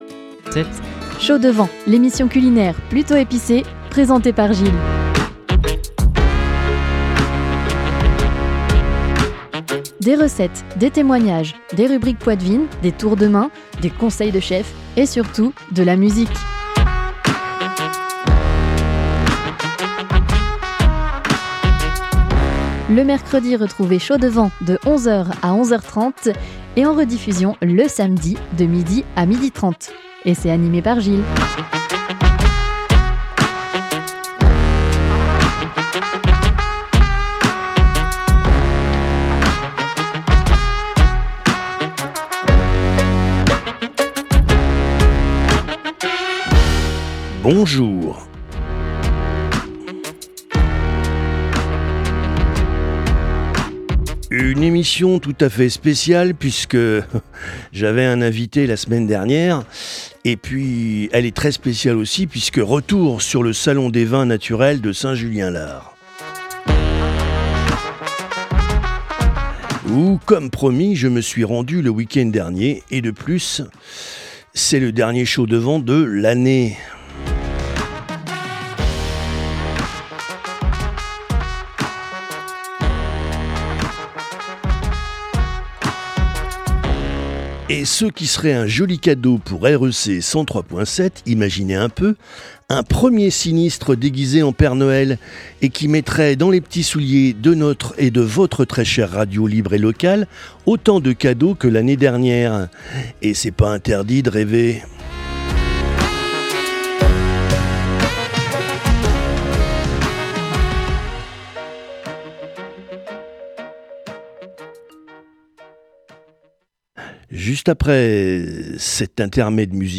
Aujourd’hui vous n’échapperez pas à mon Billet D’Humeur , et puis un retour sur le salon des vins naturels du week-end dernier à Saint Julien Lars , et l’Association DE NATURA VINI , retrouvez aussi mes interviews avec des viticultrices passionnées .